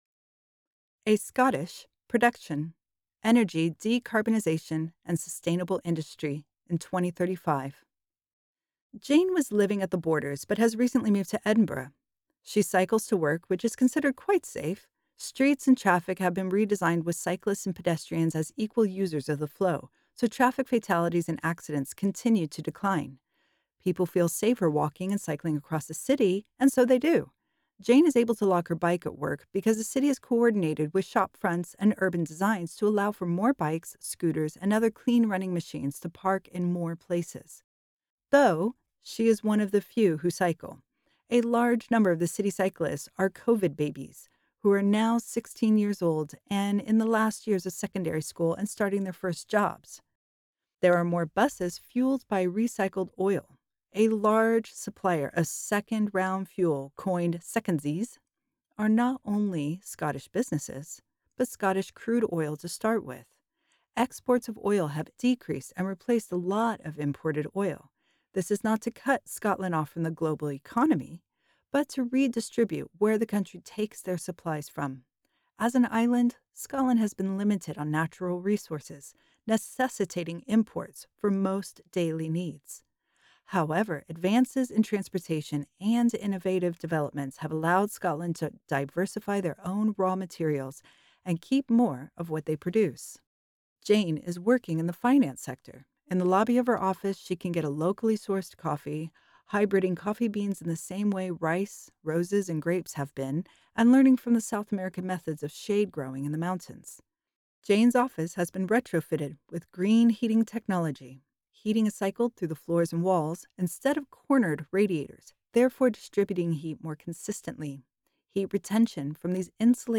Audio narration of scenario “A Scottish Production”